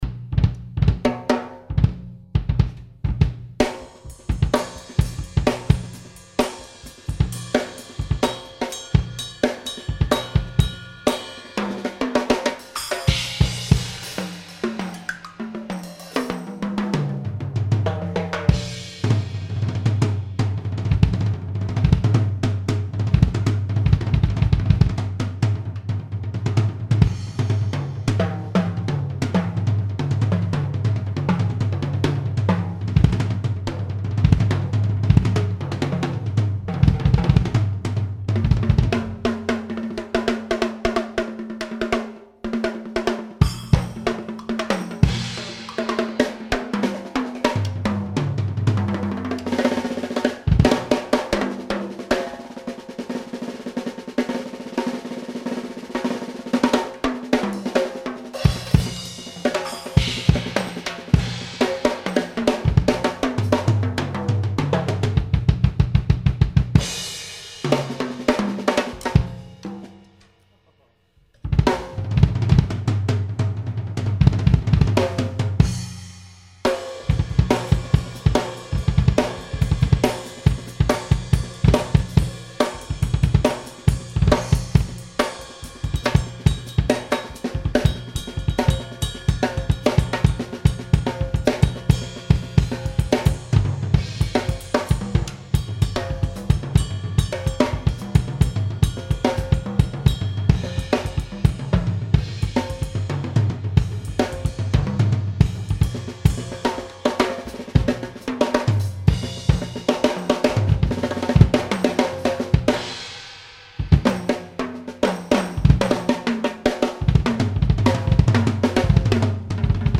Drum solo to tape ;)